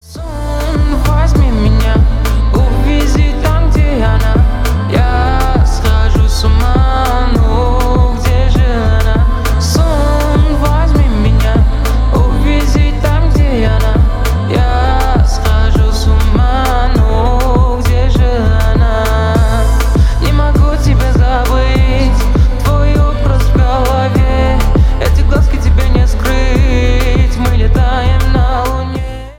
Stereo
Поп
клубные